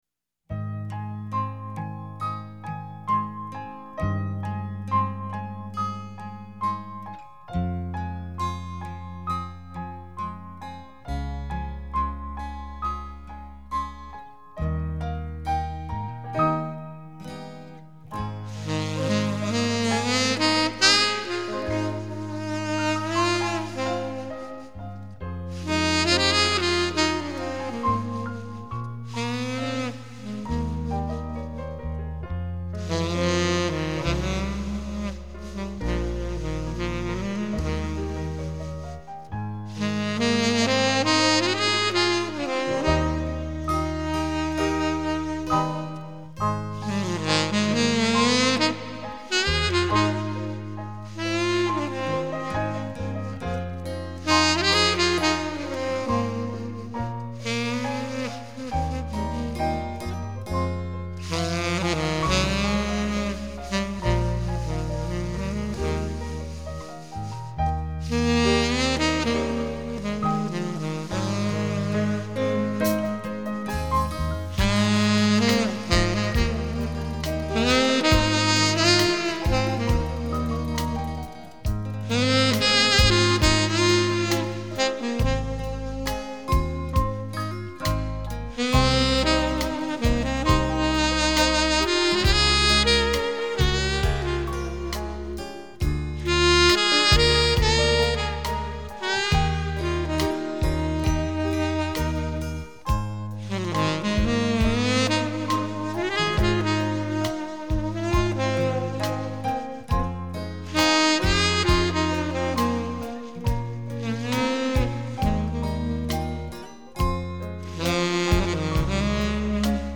相关链接 萨克斯风